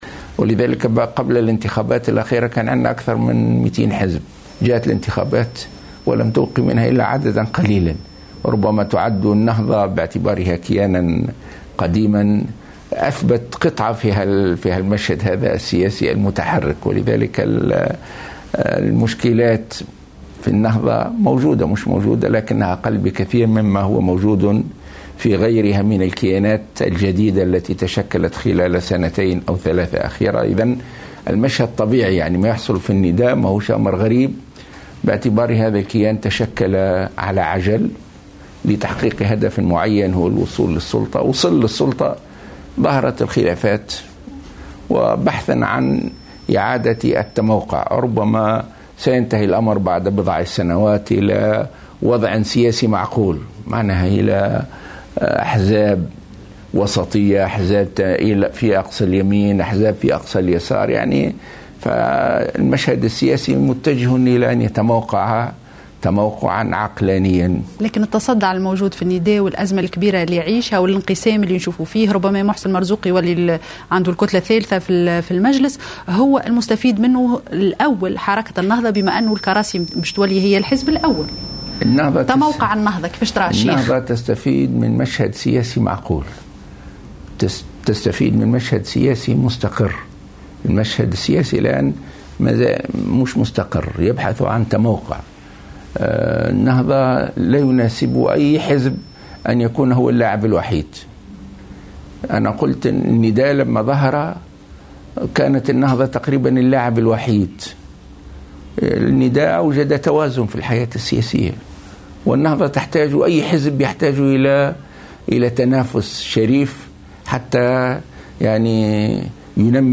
Le Président du Mouvement Ennahdha, Rached Ghannouchi, a indiqué lors d'une interview accordée à Al Wataniya 1 ce vendredi 15 janvier 2016, que des conflits existent également au sein de son parti, comme dans tous les mouvements qui ont vu le jour après la Révolution.